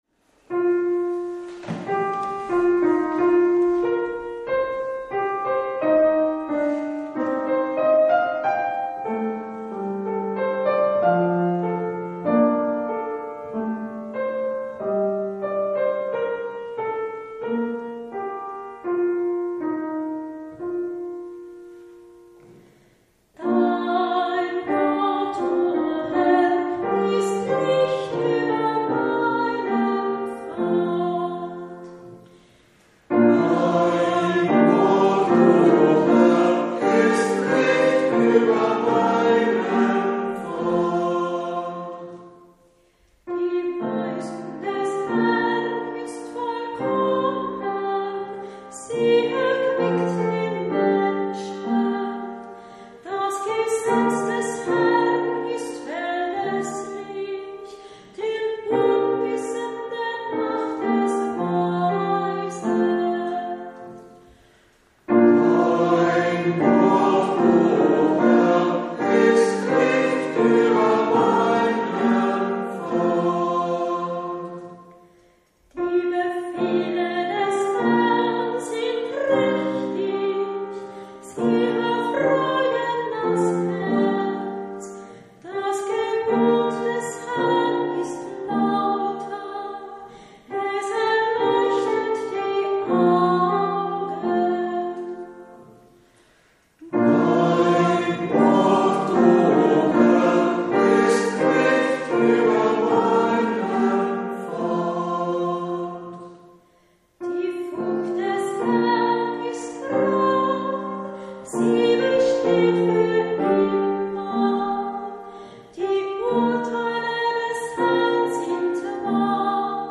Hörbeispiele aus verschiedenen Kantorenbüchern